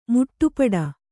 ♪ muṭṭupaḍa